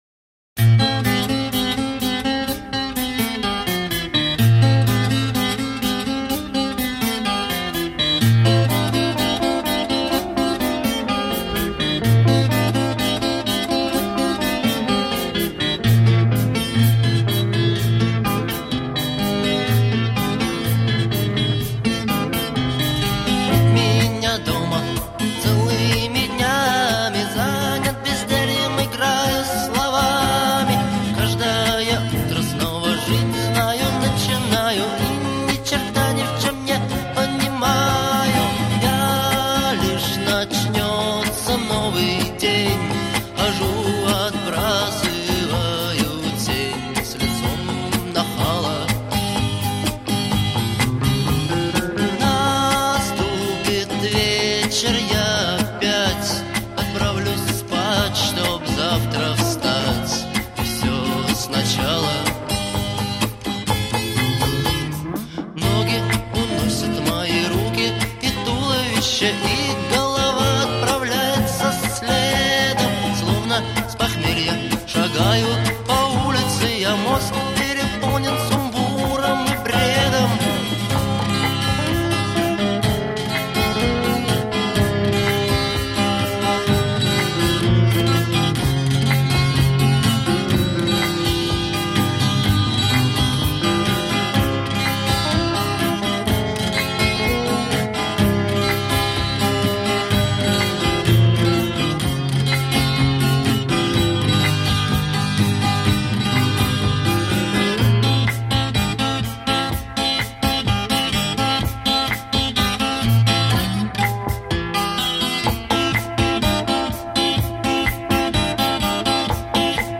Genere: Rock